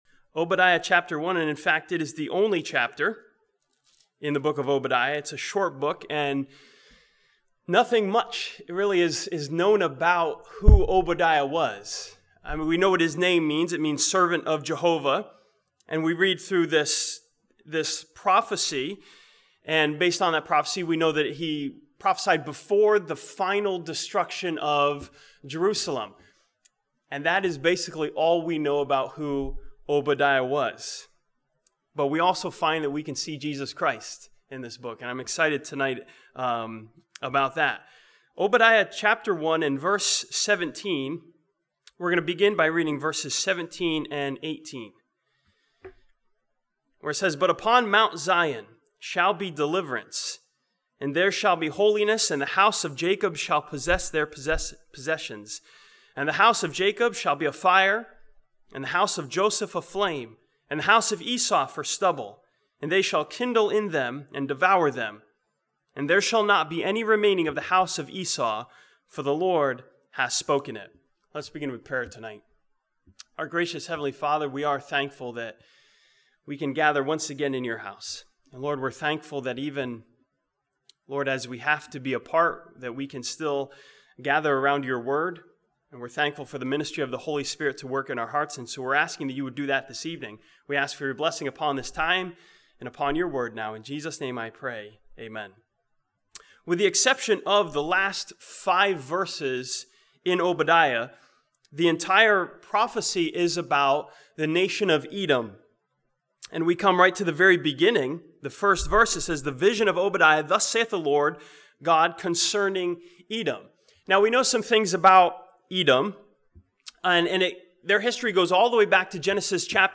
This sermon from Obadiah chapter 1 sees Jesus as the Final Savior of Israel and the Final Savior for the believer.